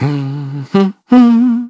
Evan-Vox_Hum.wav